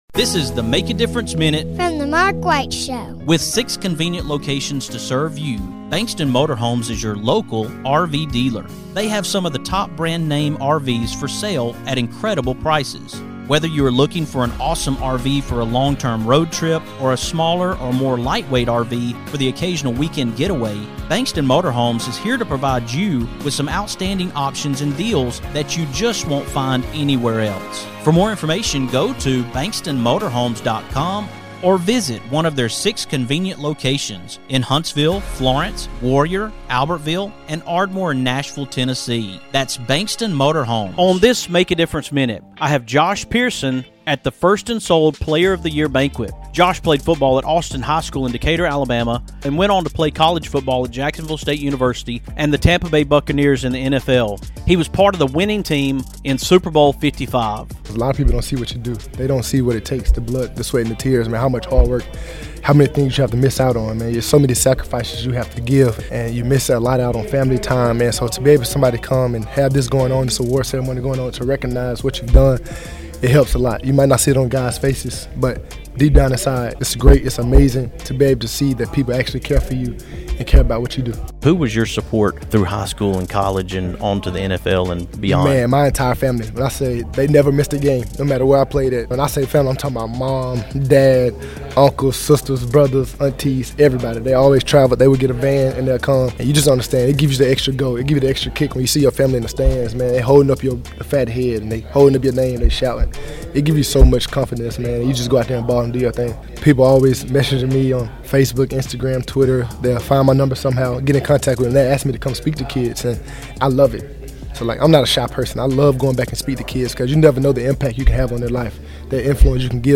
On today’s MADM, I’m coming to you from 1st & Sold-MarMac Real Estate Football in the South Player of the Year Banquet!